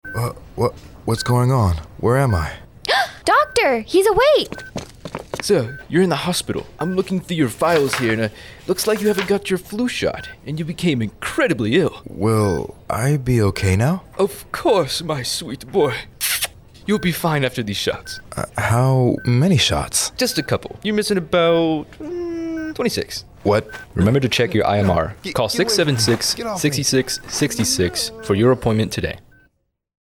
AFN INCIRLIK RADIO SPOT: IMR Get Your Shots